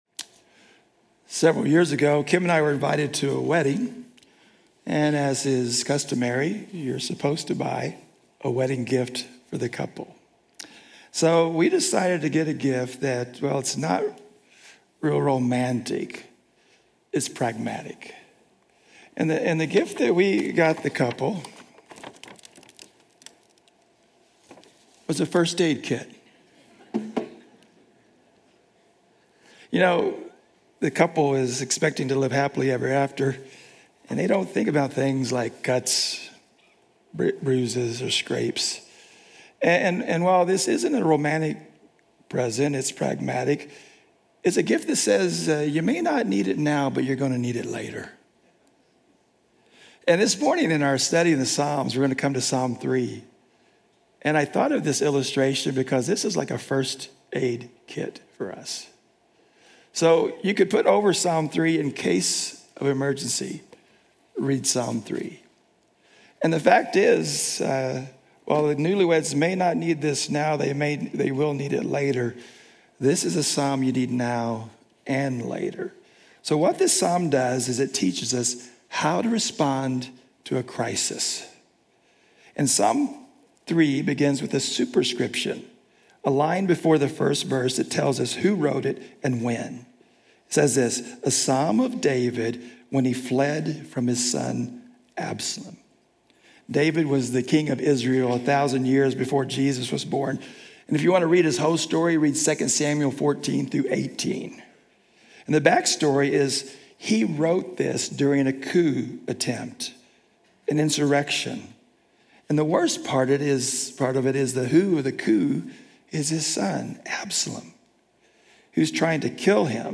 Journey Church Bozeman Sermons Summer In The Psalms: How to Respond in a Crisis?